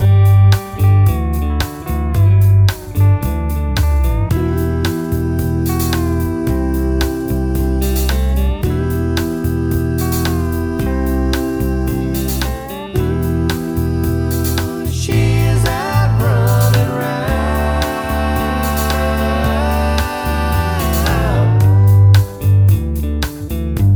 No Guitars Soft Rock 2:53 Buy £1.50